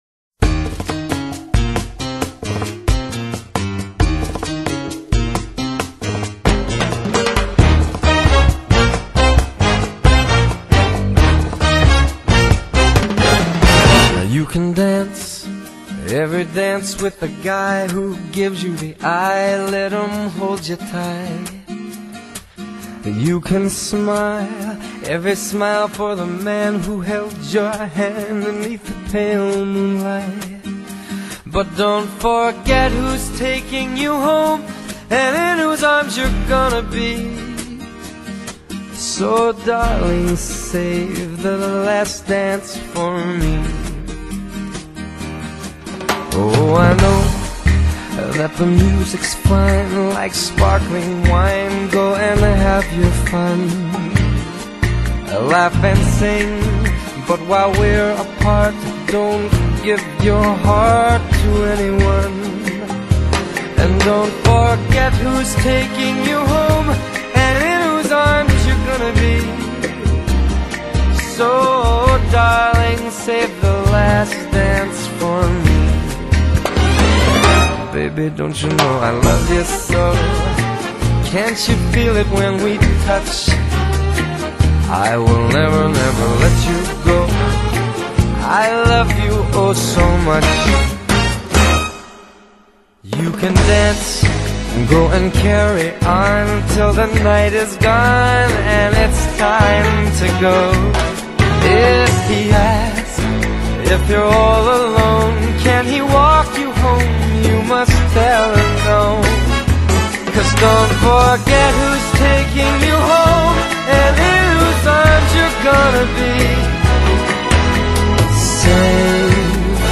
音乐类型：爵士乐
制成这首超high的摇摆乐听觉飨宴